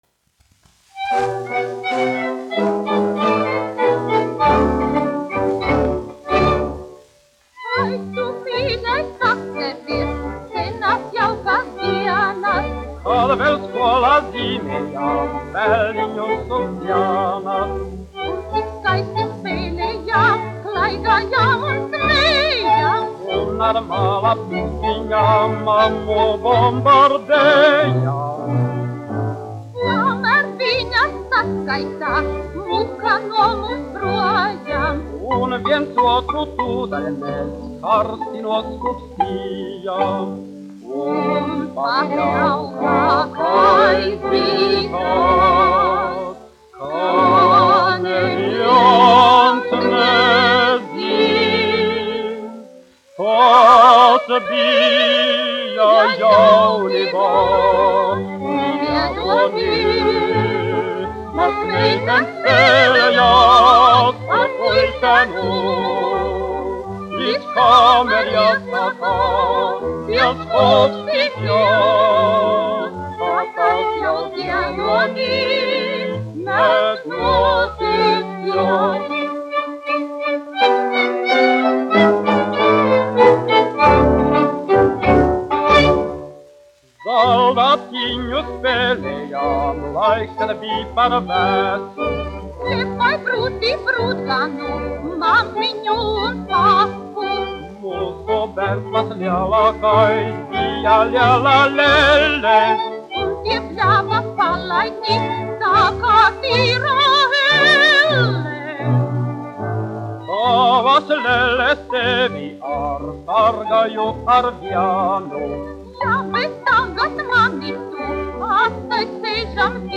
1 skpl. : analogs, 78 apgr/min, mono ; 25 cm
Dziesmas
Latvijas vēsturiskie šellaka skaņuplašu ieraksti (Kolekcija)